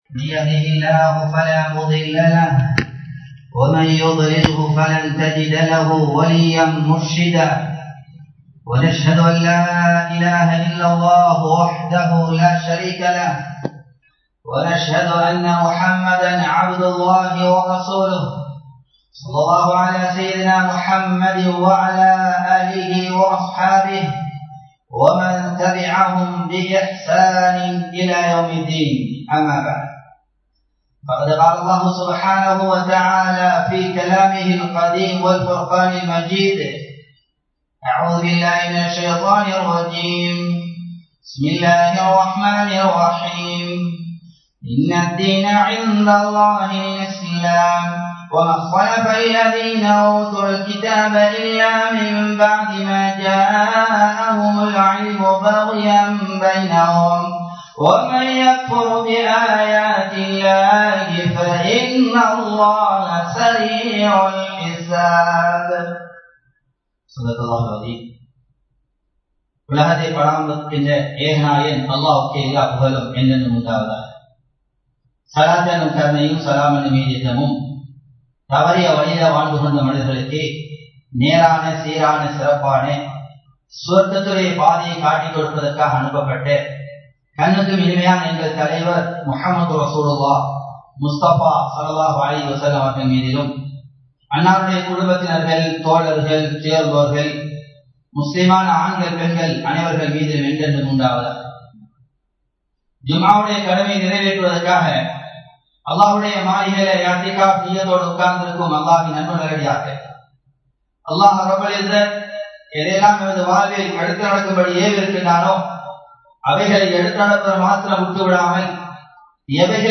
தொற்று நோயும் இஸ்லாமிய வழிகாட்டலும் | Audio Bayans | All Ceylon Muslim Youth Community | Addalaichenai
Muhiyadeen Jumua Masjith